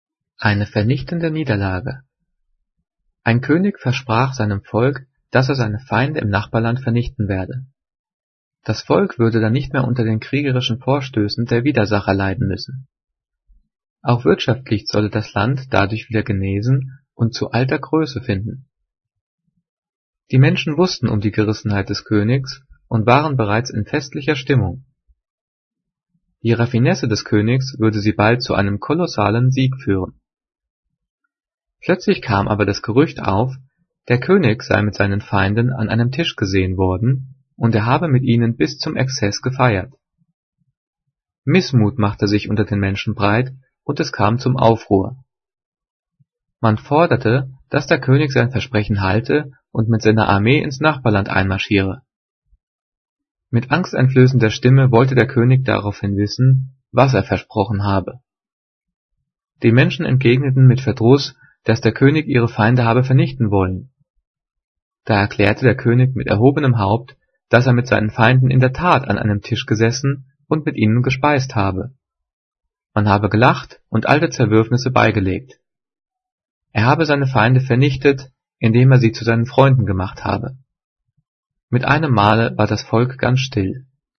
Gelesen:
gelesen-eine-vernichtende-niederlage.mp3